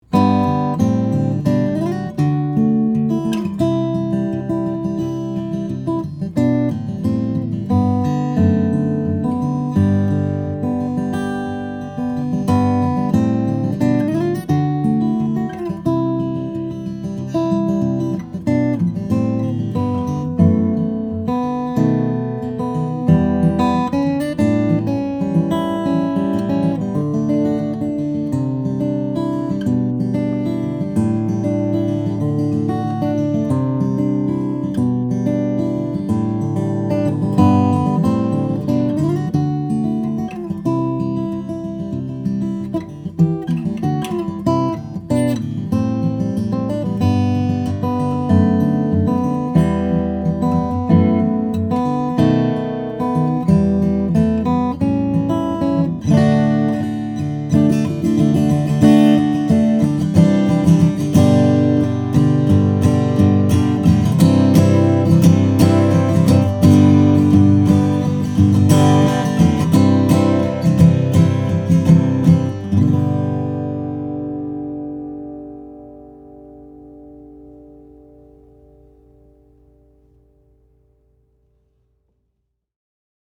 Sugar sweet with power to match.